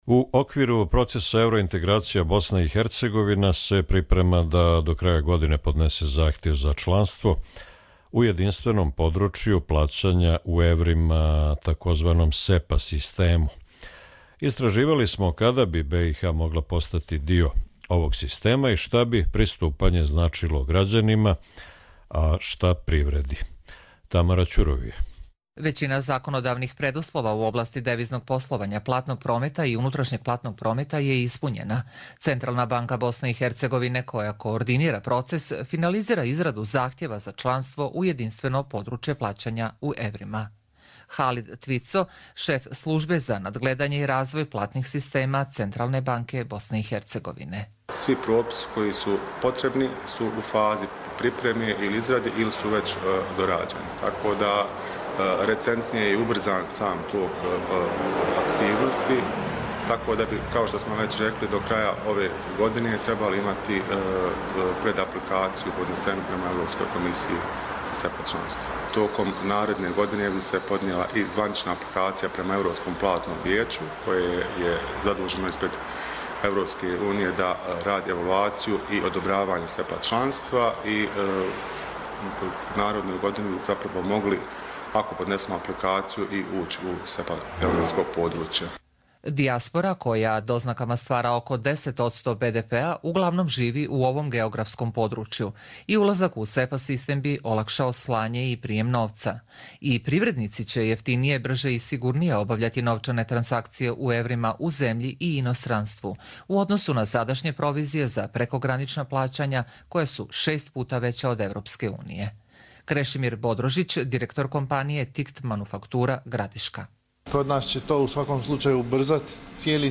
Radio reportaža